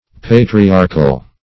Patriarchal \Pa`tri*ar"chal\, a. [Cf. F. patriarcal.]